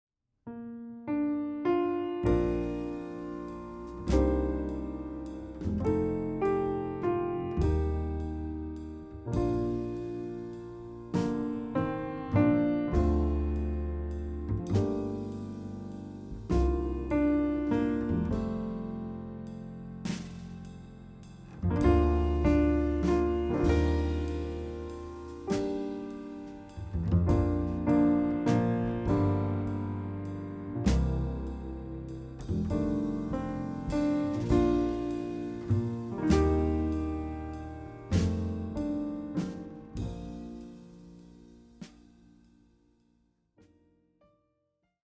Scottish folk song
bass
piano
drums